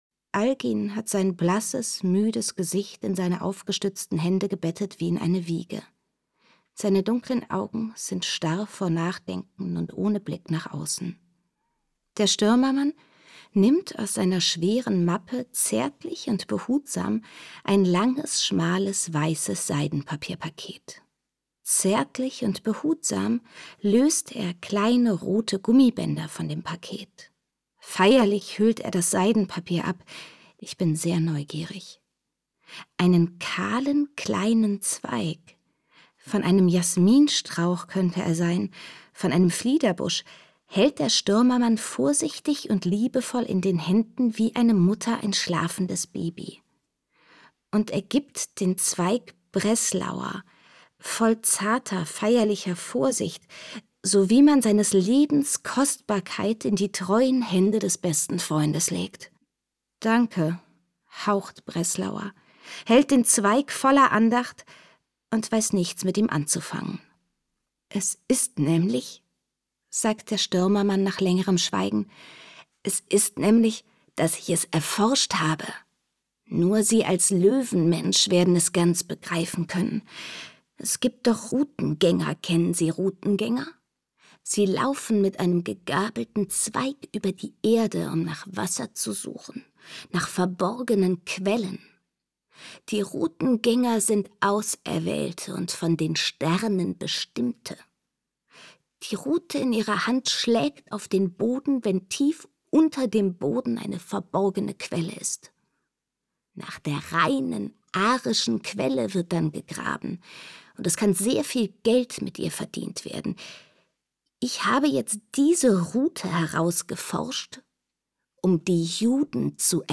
Irmgard Keun: Nach Mitternacht (11/15) ~ Lesungen Podcast